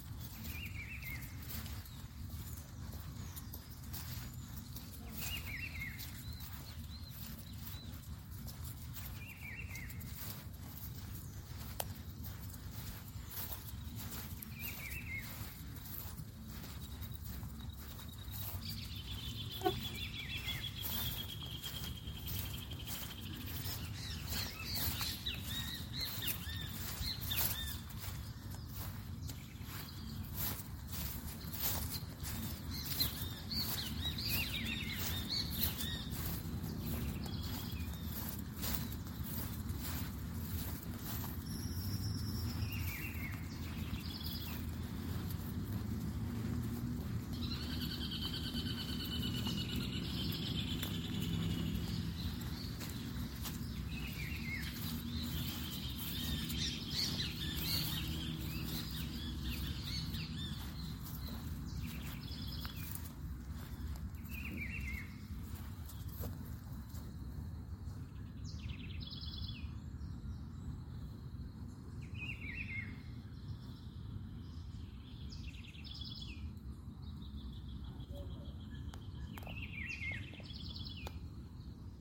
Juan Chiviro (Cyclarhis gujanensis)
Nombre en inglés: Rufous-browed Peppershrike
Provincia / Departamento: Tucumán
Localidad o área protegida: San Miguel, capital
Condición: Silvestre
Certeza: Vocalización Grabada